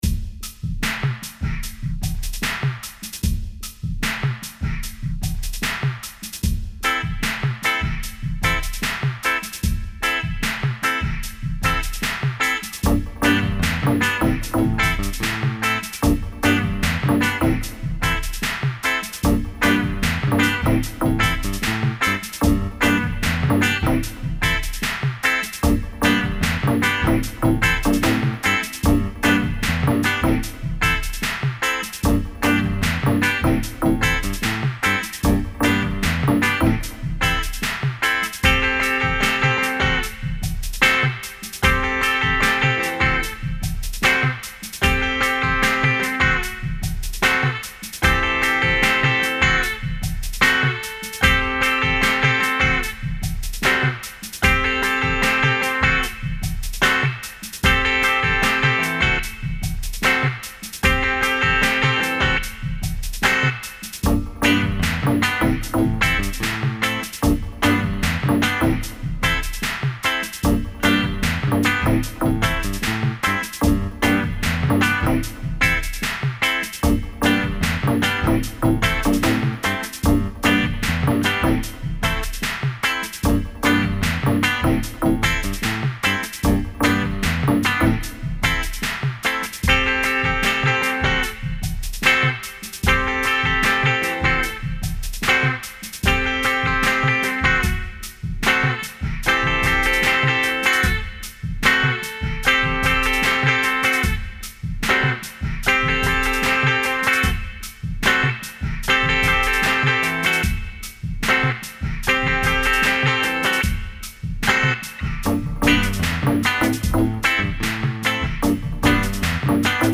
Versão instrumental: